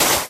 sand3.ogg